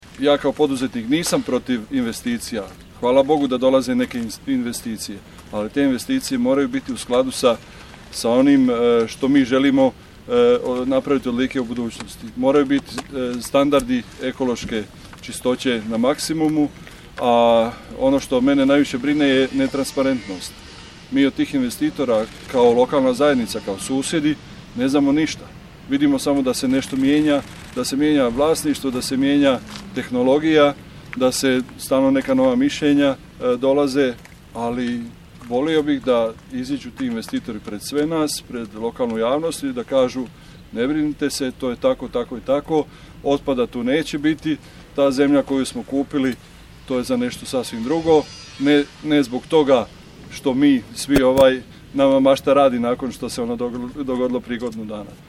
Ispred novoizgrađene tvornice za obradu litija u poslovnoj zoni Smiljansko polje u Gospiću danas je održana press konferencija Mosta, na kojoj je upozoreno na nedostatak transparentnosti projekta i moguće posljedice po okoliš i zdravlje građana.